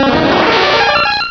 pokeemerald / sound / direct_sound_samples / cries / paras.aif